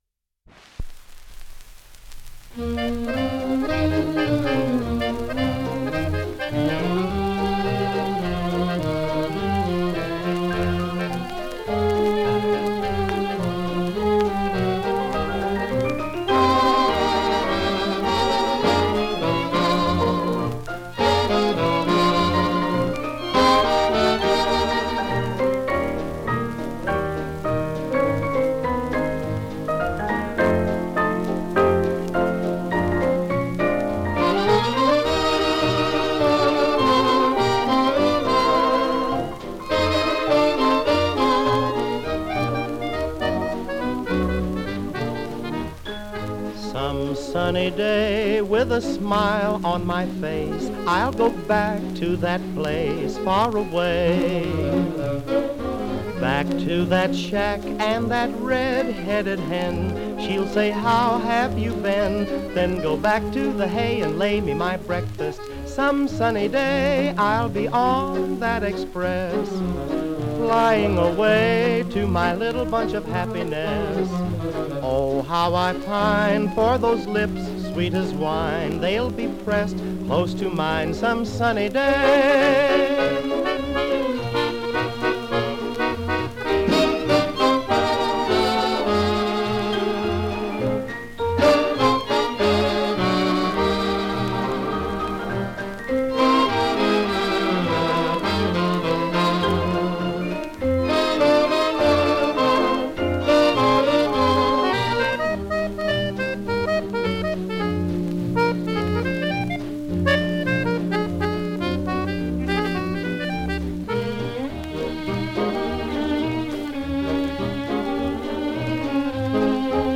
Genre: Popular Music.